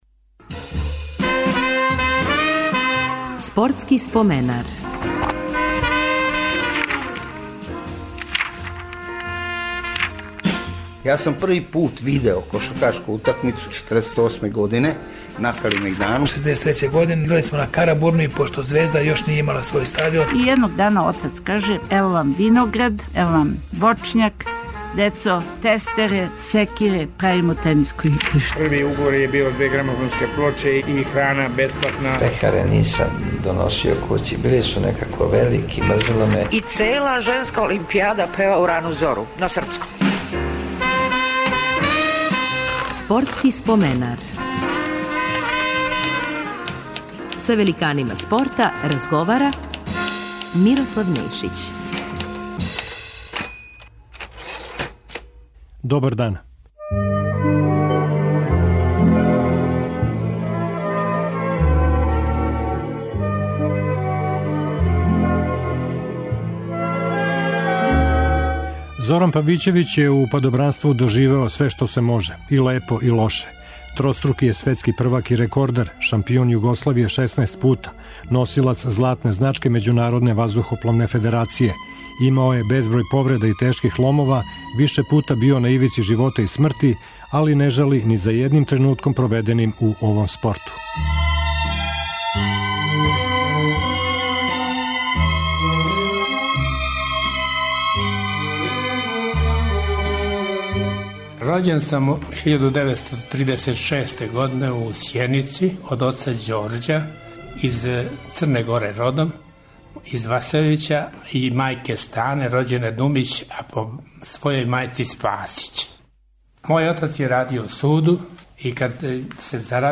Радио Београд 1 од 16 до 17 часова.